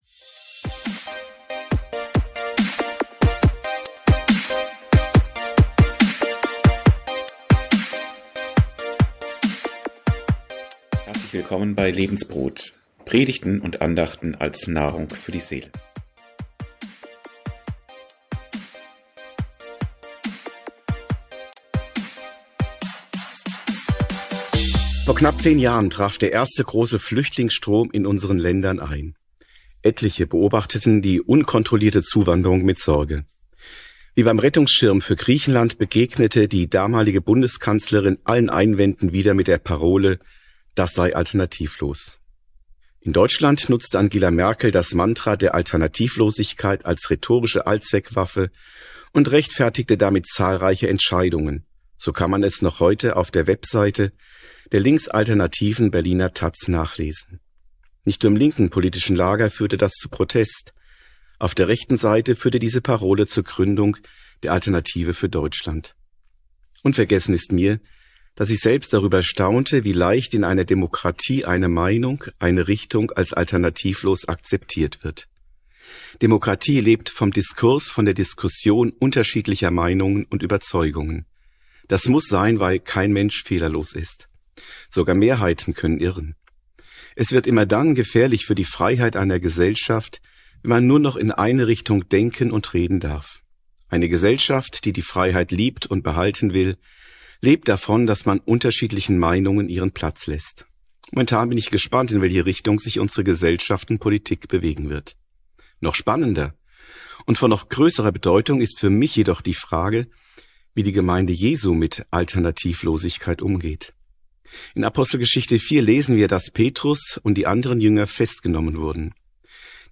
Echte und falsche Alternativlosigkeit ~ Predigten u. Andachten (Live und Studioaufnahmen ERF) Podcast